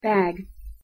pronunciation-en-bag.mp3
LAxfmXwWEMo_pronunciation-en-bag.mp3